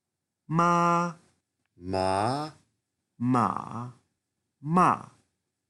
표준 중국어 4성조 발음